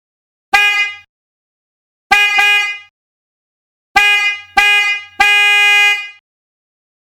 ※音は試聴用に録音したもので実際の吹鳴音と異なる場合があります。
渦巻ホーンの特長である柔らかなサウンドを奏でます。
400Hz